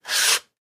in_sandpaper_stroke_04_hpx
Wood being sanded by hand. Tools, Hand Wood, Sanding Carpentry, Build